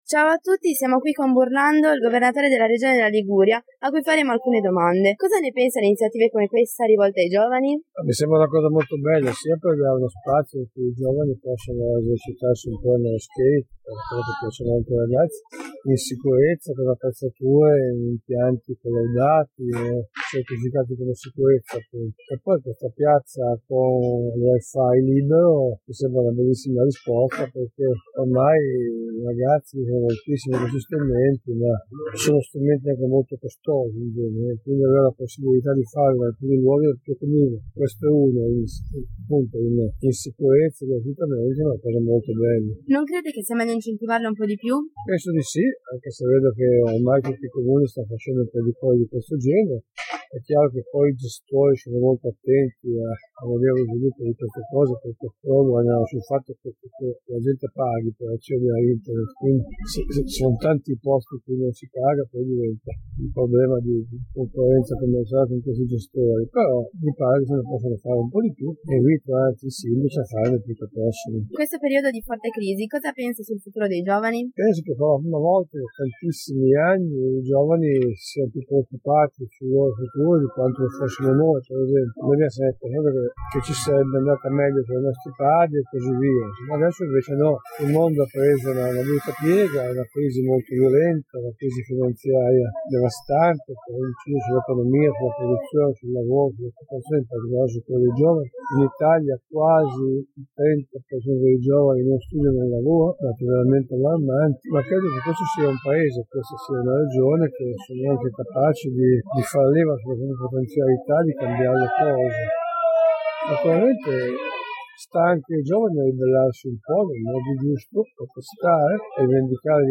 Intervista a Claudio Burlando
Durante l'inaugurazione dello skate park del centro, i ragazzi hanno intervistato il Presidente della Regione Liguria